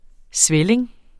Udtale [ ˈsvεleŋ ]